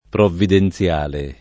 provvidenZL#le] agg. («della provvidenza divina») — più spesso per iperbole: un intervento p., per risolvere un problema; una pioggia p., per un buon raccolto — diverso da previdenziale